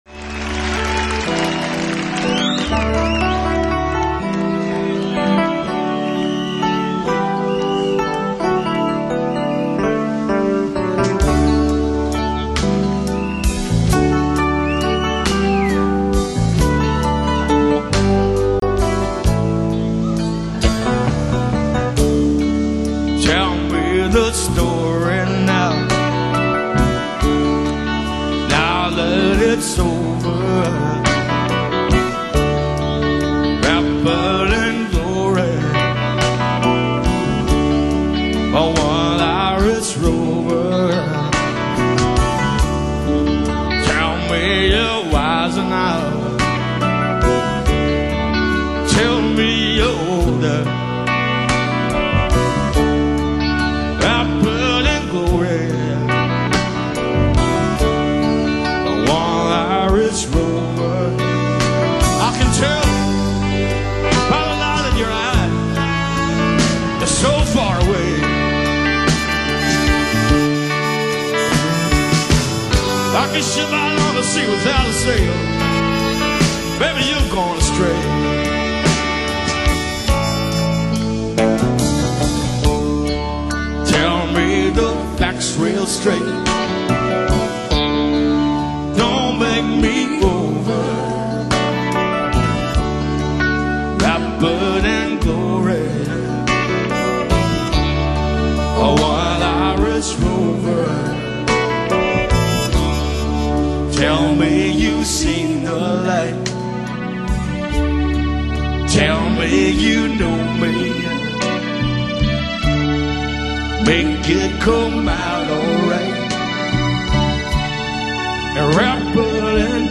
Not so much Rock as much as a sort of Jazz/Blues hybrid